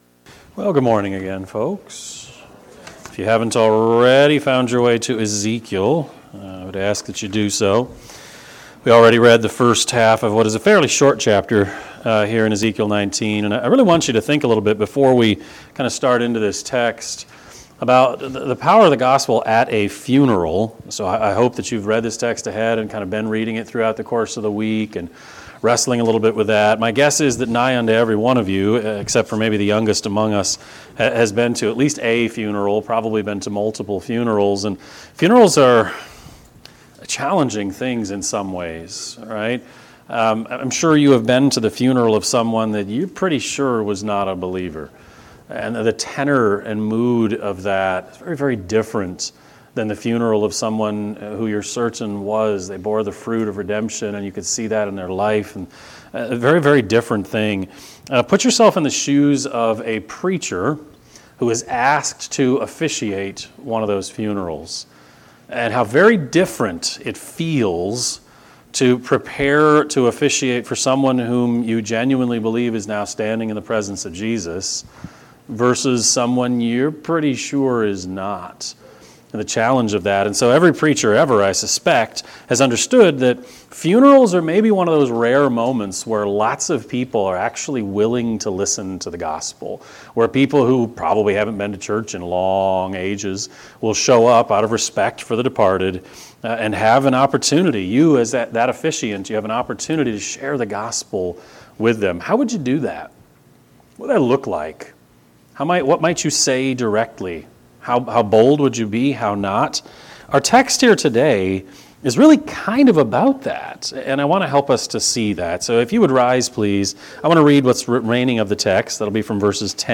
Sermon-9-29-24-Edit.mp3